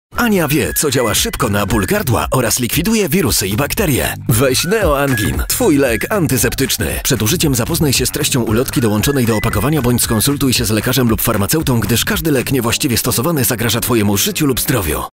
Male 20-30 lat
Young, vital and dynamic voice, known from VIVA Polska and NICKELODEON.
Spot reklamowy